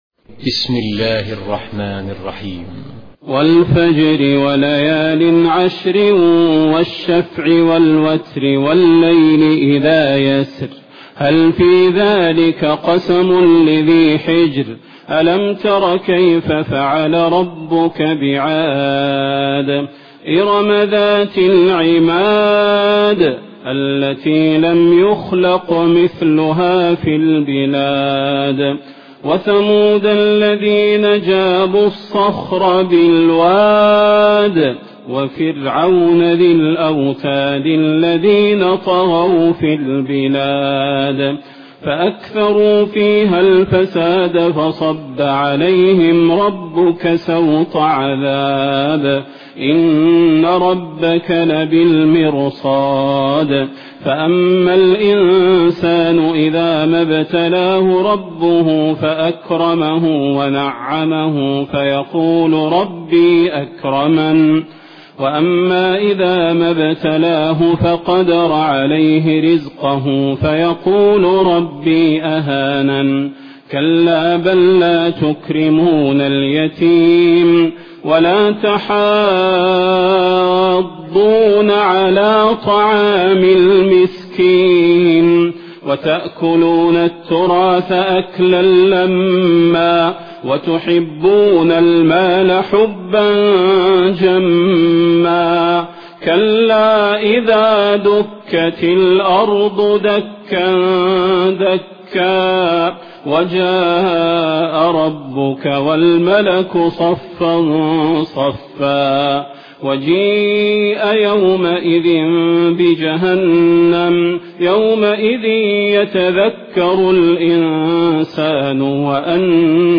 Taraweeh Prayer 1437